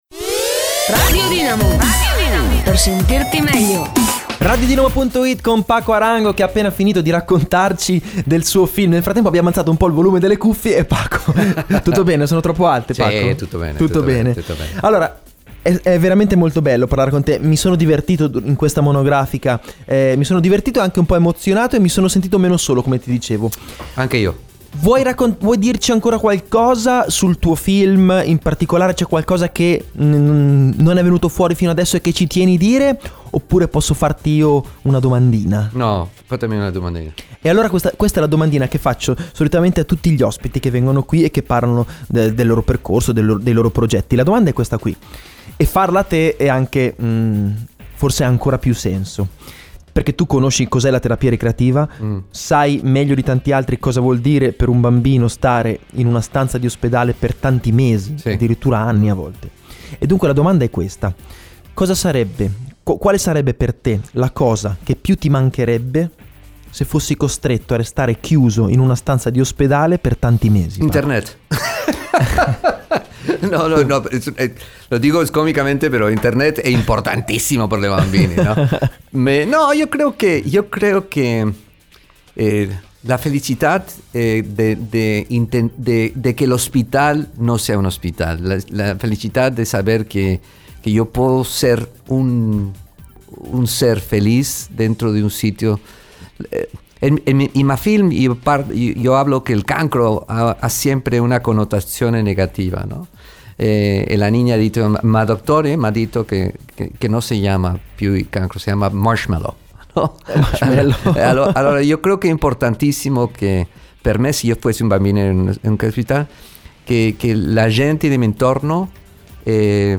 LA DIRETTA!!!
LA DIRETTA CON PACO ARANGO!!